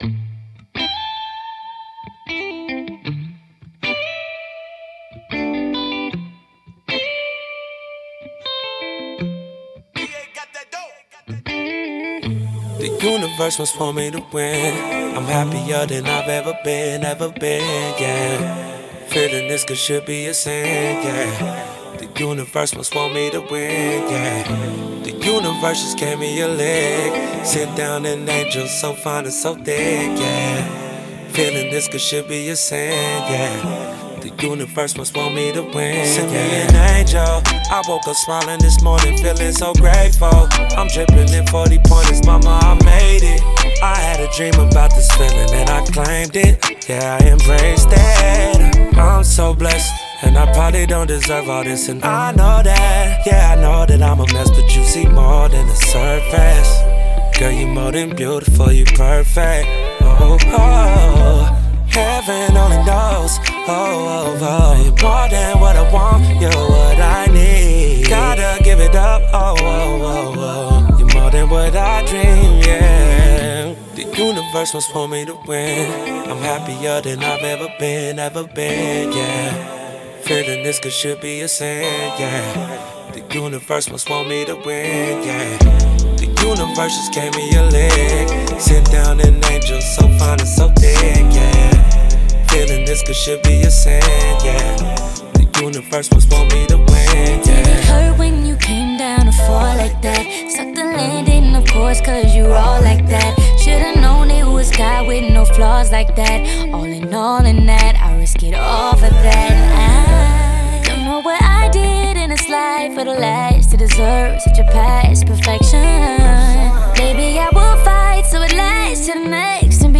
Rapper and R&B singer-songwriter